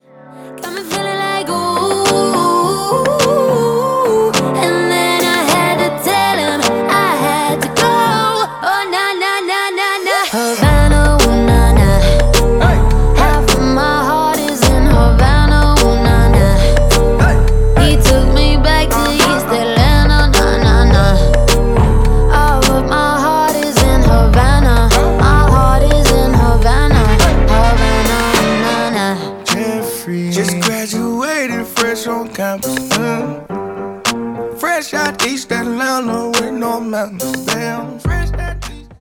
• Качество: 320, Stereo
женский вокал
заводные
красивый женский голос
латина
Latin Pop